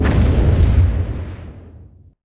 NanoWarpFlash.wav